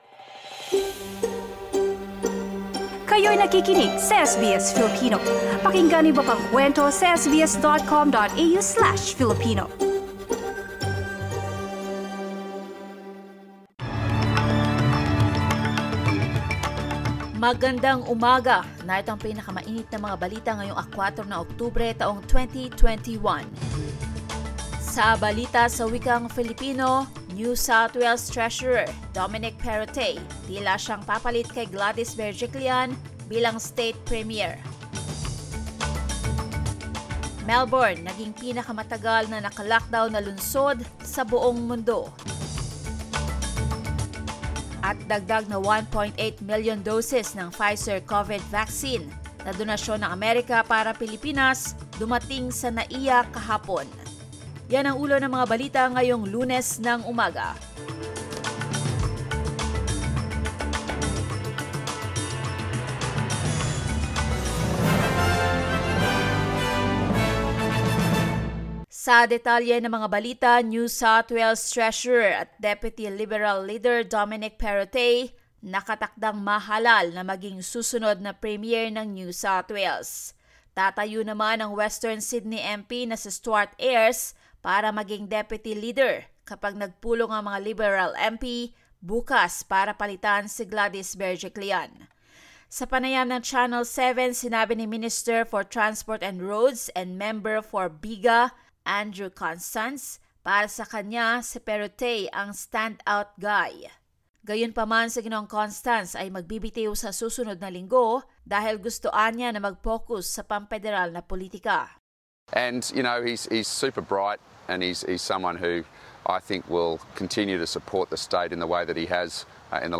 SBS News in Filipino, Monday 4 October